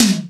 XRHiTom.wav